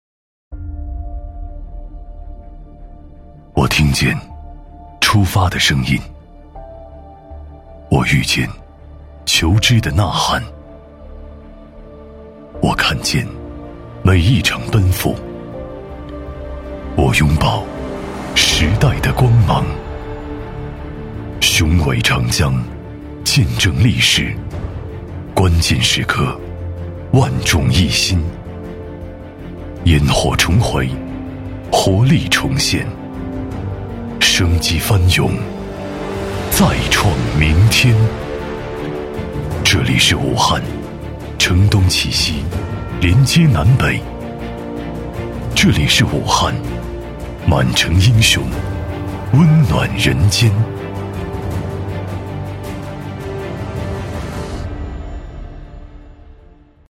男98-微电影【呐 喊-磁性有力】
男98-磁性品质 深情感人
男98-微电影【呐 喊-磁性有力】.mp3